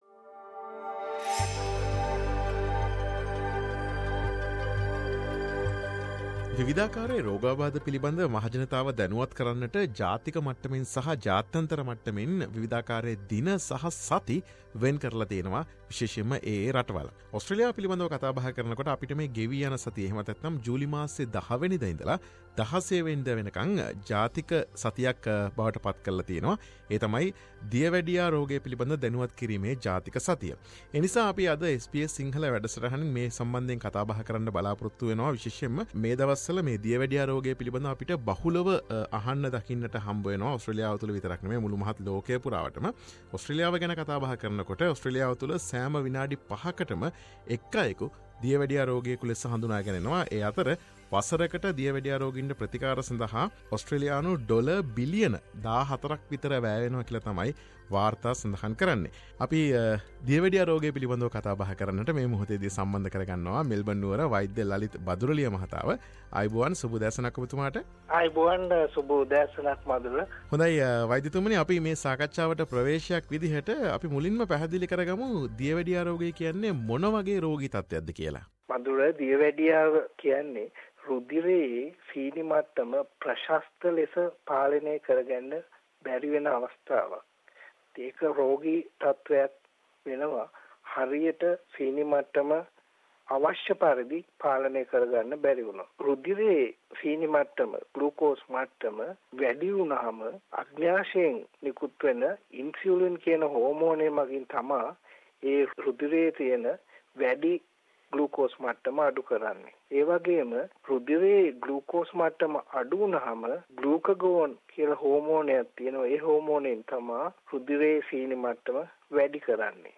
Health discussion